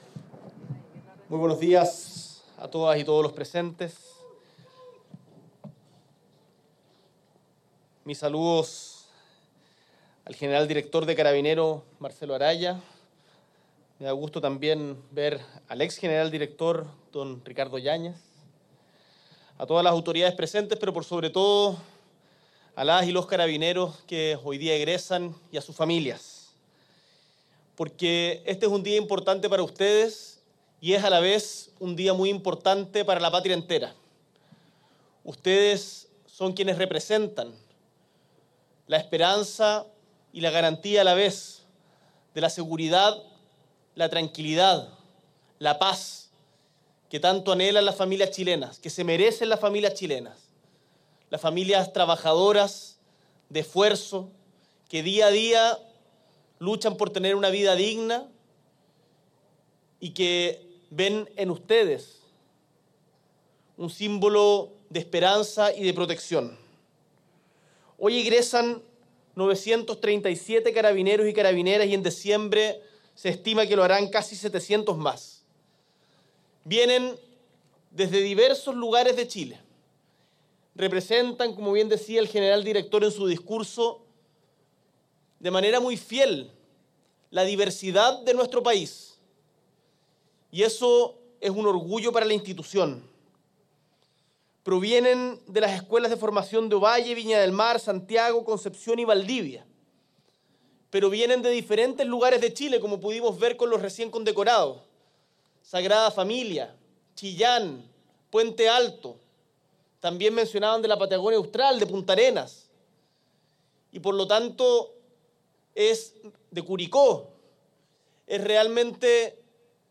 S.E. el Presidente de la República, Gabriel Boric Font, participa de la ceremonia de egreso de la Escuela de Formación de Carabineros de Chile